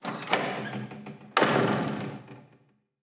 Abrir o cerrar una puerta de madera
Sonidos: Acciones humanas
Sonidos: Hogar